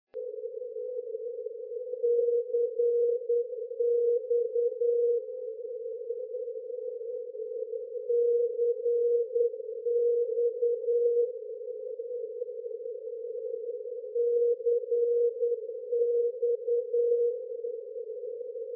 NDB Non Directional Beacon ADF